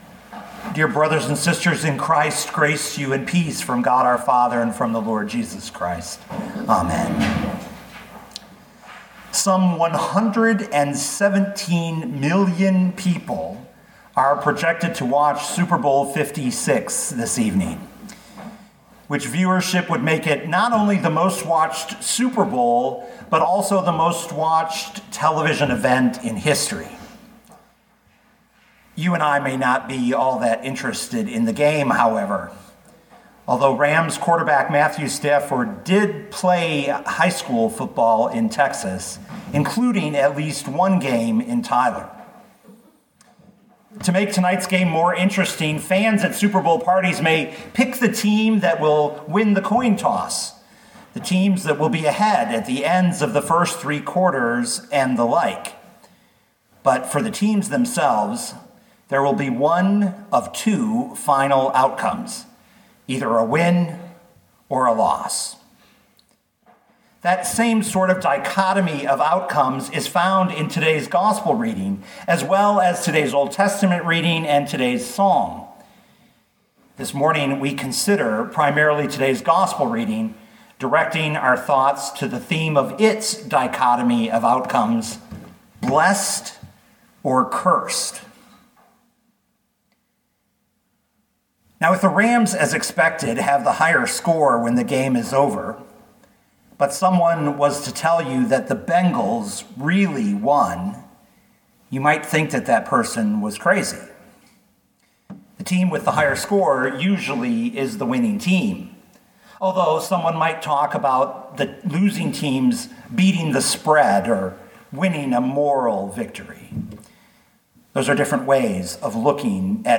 2022 Luke 6:17-26 Listen to the sermon with the player below, or, download the audio.